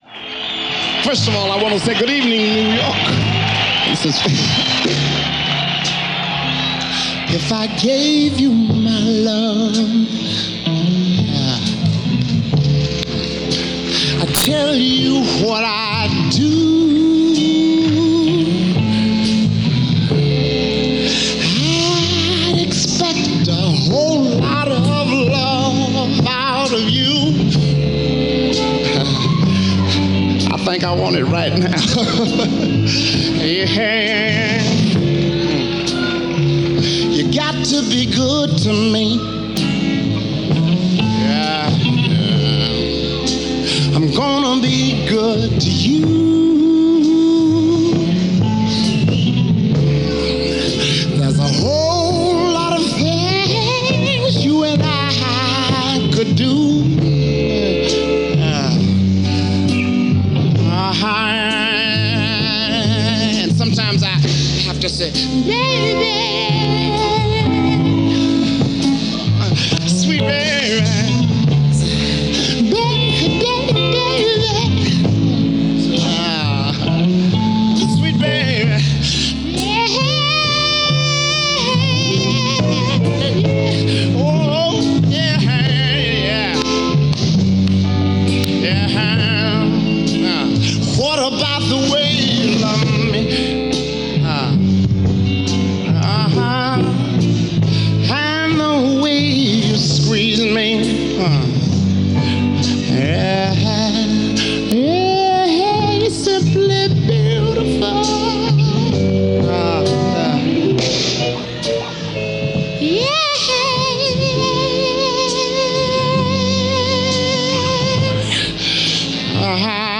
Bananafish Garden, Brooklyn